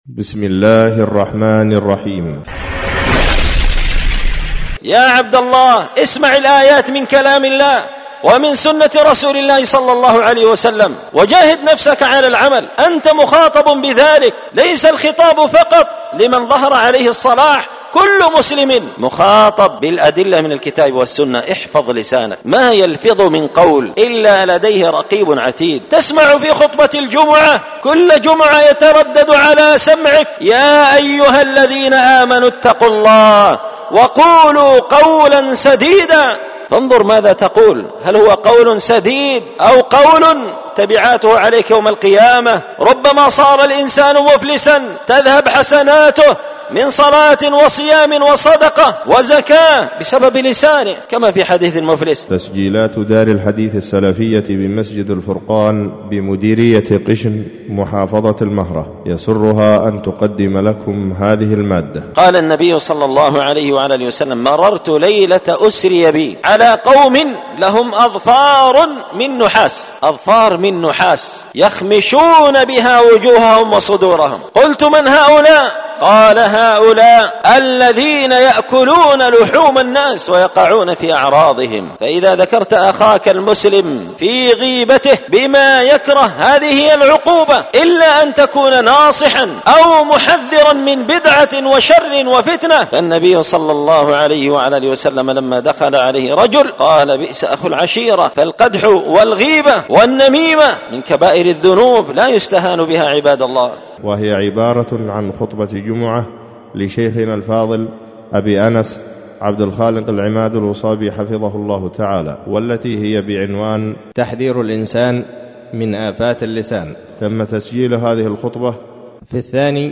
ألقيت هذه الخطبة بدار الحديث السلفية بمسجد الفرقان قشن-المهرة-اليمن ت…